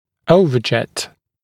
[‘əuvəʤet][‘оувэджэт]горизонтальное перекрытие (резцовое), сагиттальная щель